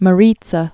(mə-rētsə)